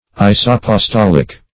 Meaning of isapostolic. isapostolic synonyms, pronunciation, spelling and more from Free Dictionary.
Search Result for " isapostolic" : The Collaborative International Dictionary of English v.0.48: Isapostolic \Is*ap`os*tol"ic\, a. [Gr.